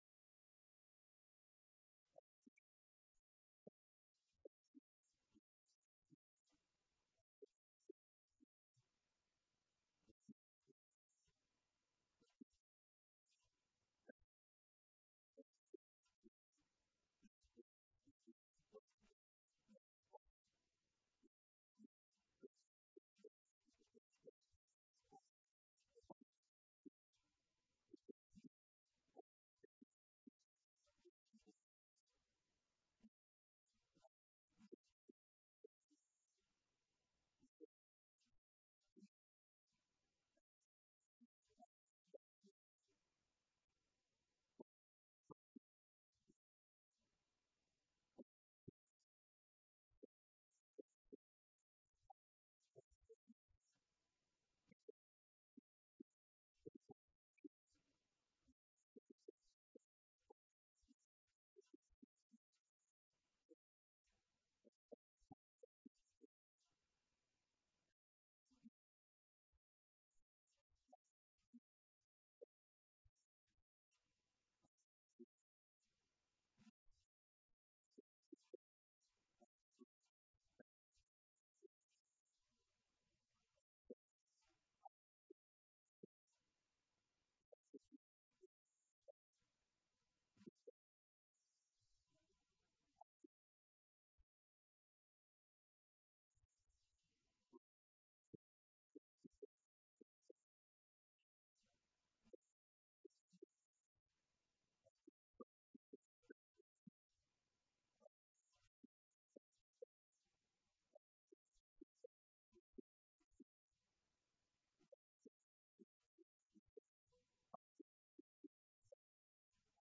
Event: 2019 Men's Development Conference
lecture